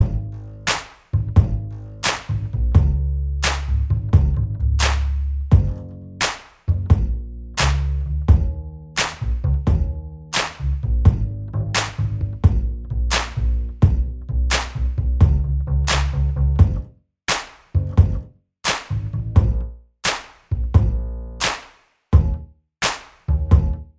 Minus Acoustic Guitar Pop (2010s) 3:07 Buy £1.50